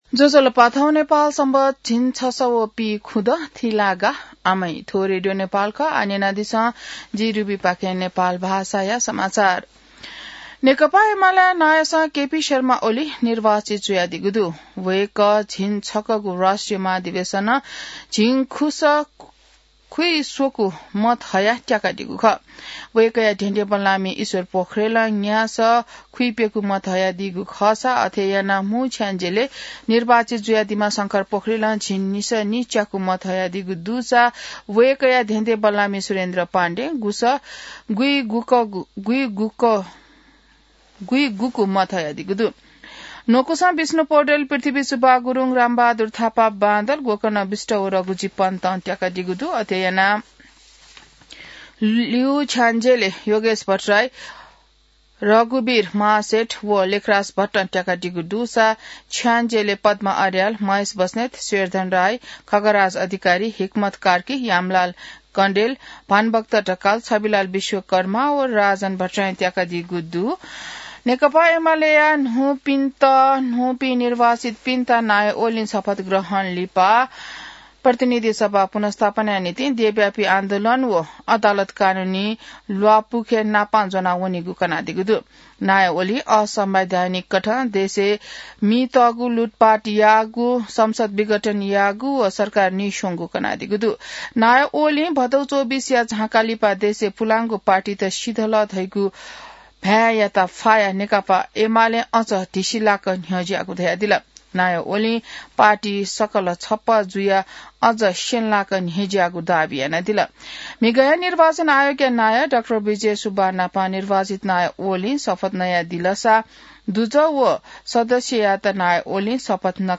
नेपाल भाषामा समाचार : ४ पुष , २०८२